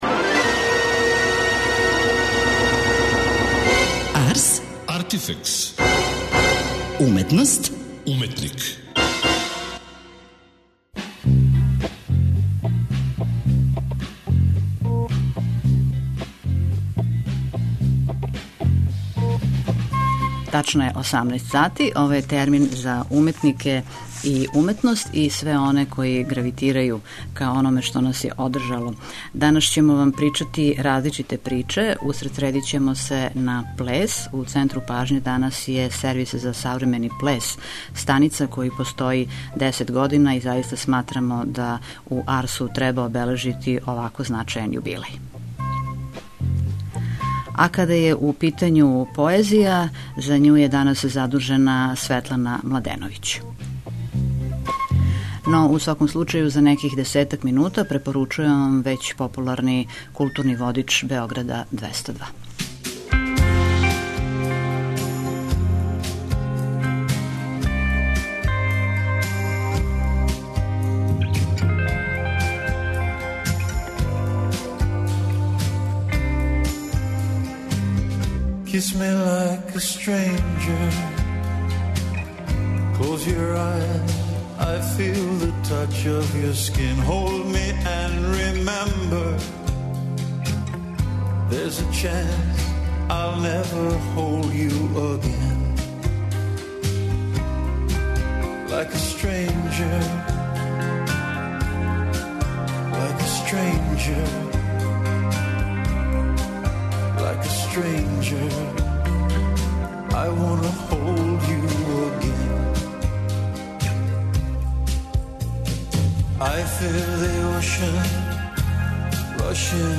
Колико поезија живи ван ових свечарских дана, тема је о којој у данашњој емисији говоре песници из Србије и Хрватске.